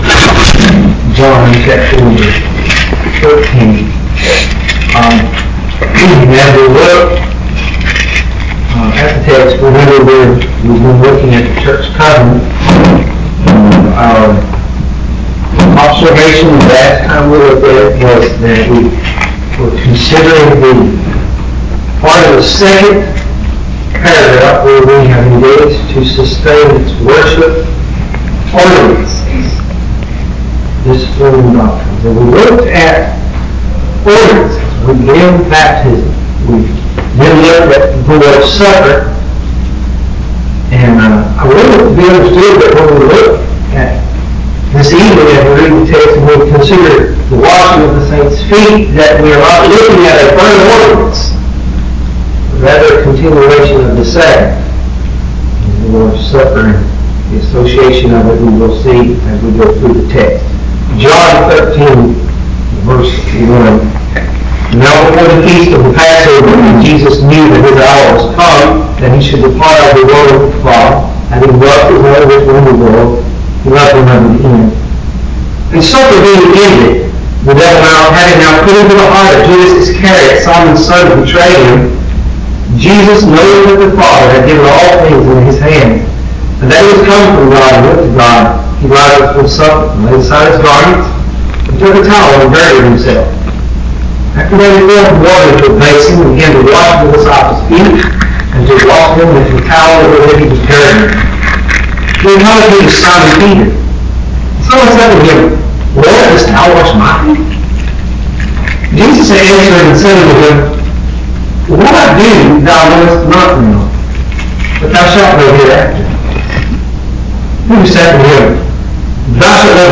Sermon: The Washing Of The Saints’ Feet (Please pardon the poor quality.)